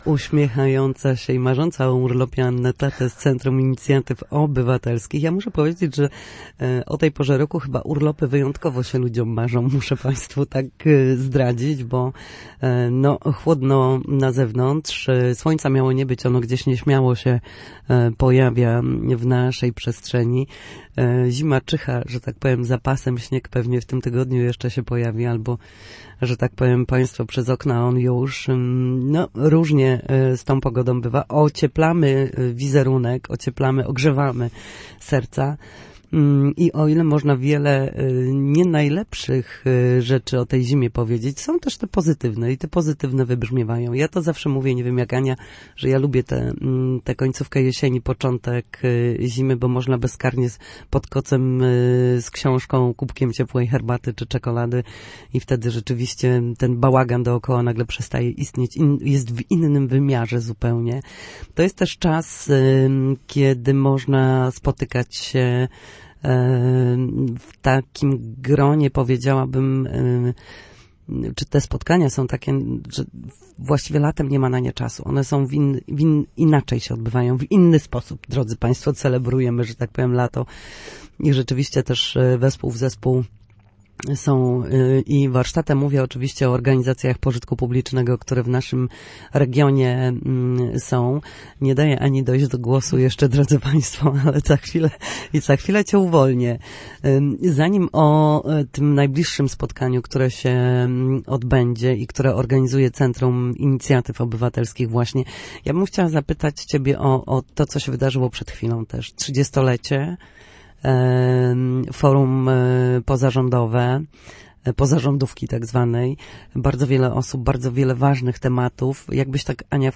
Na antenie naszego radia mówiła o obchodzonych niedawno trzydziestych urodzinach CIO, zjeździe przedstawicieli trzeciego sektora w ramach Pomorskiego Forum Inicjatyw Pozarządowych (PFIP) oraz jutrzejszej Wigilii